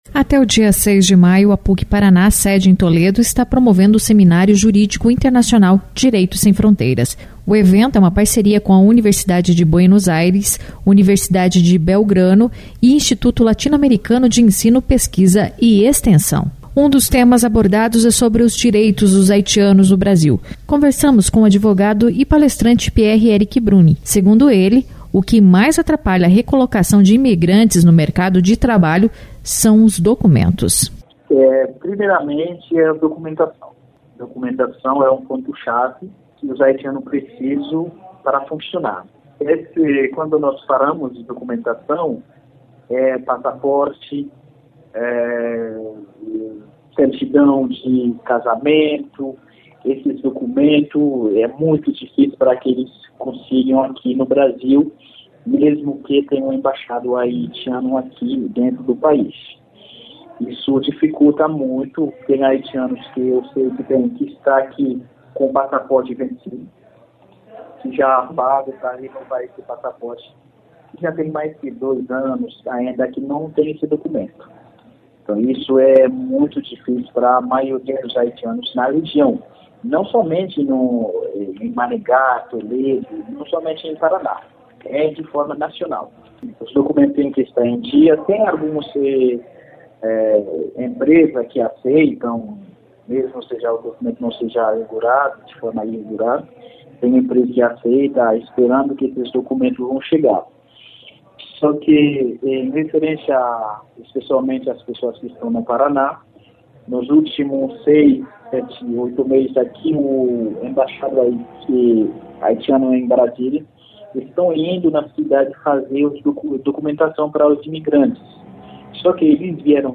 conversou com a CBN Cascavel sobre as maiores dificuldades encontradas pelos haitianos para que se estabeleçam no país.